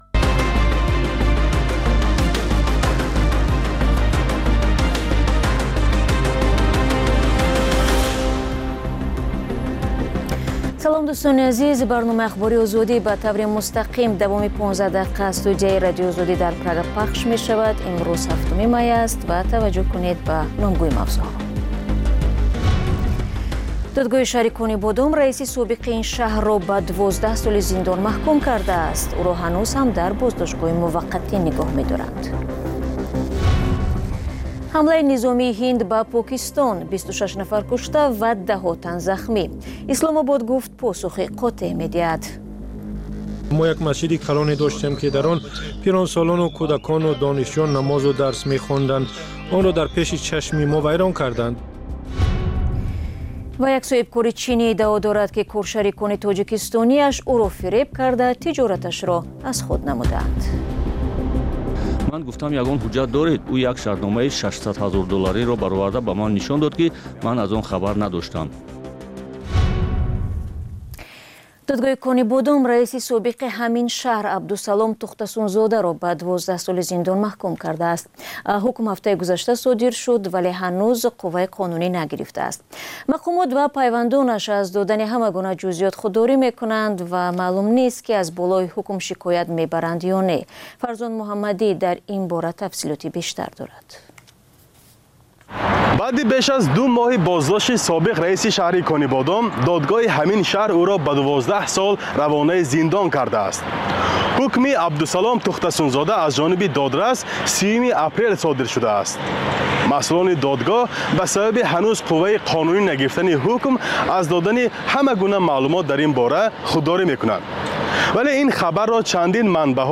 Пахши зинда